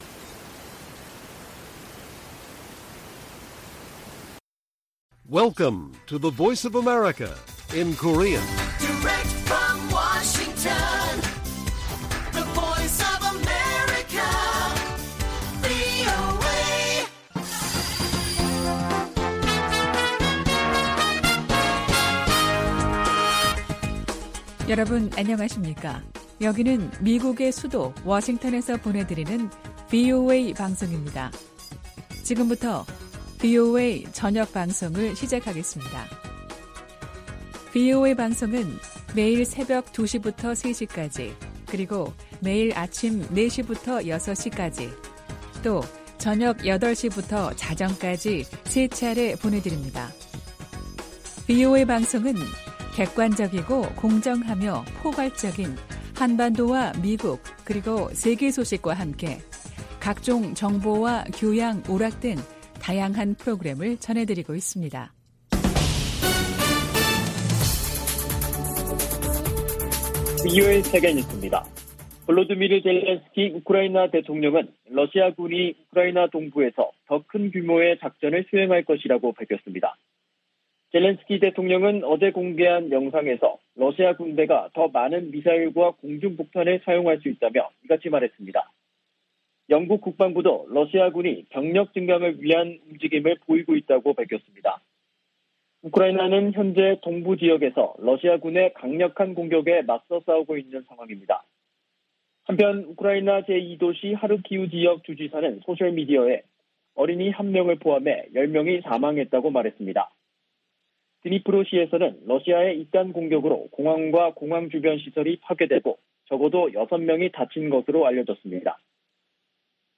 VOA 한국어 간판 뉴스 프로그램 '뉴스 투데이', 2022년 4월 11일 1부 방송입니다. 미 국무부는 북한 비핵화 목표에 정책 변화가 없다고 밝히고, 대화에 나오라고 북한에 촉구했습니다. 미 국방부는 북한의 위협을 잘 알고 있다며 동맹인 한국과 훈련과 준비태세를 조정하고 있다고 밝혔습니다. 북한이 추가 핵실험을 감행할 경우 핵탄두 소형화를 위한 실험일 수 있다는 전문가들의 지적이 이어지고 있습니다.